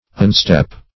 Search Result for " unstep" : The Collaborative International Dictionary of English v.0.48: Unstep \Un*step"\, v. t. [1st pref. un- + step.]